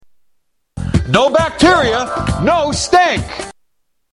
Tags: Media Doc Bottoms Aspray Doc Bottoms Aspray Ads Doc Bottoms Aspray Commercial Body Deodorant